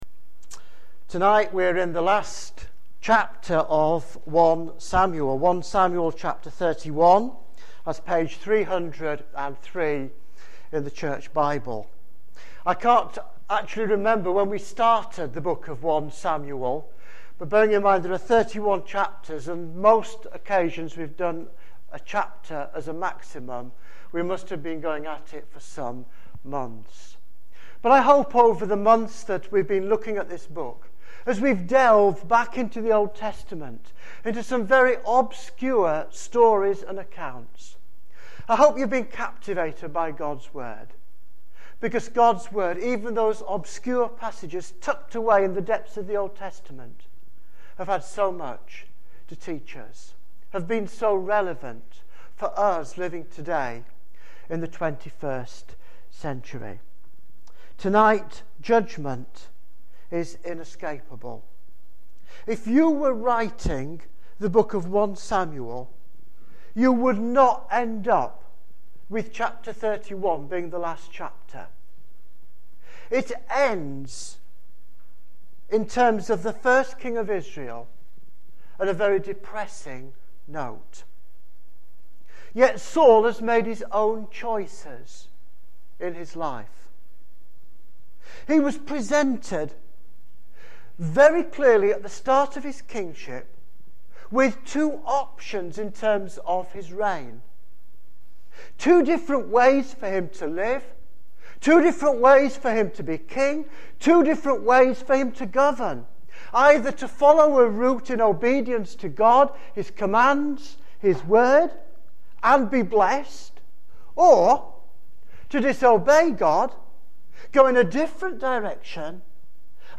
preached at Emmanuel Church, Northwich, Cheshire, UK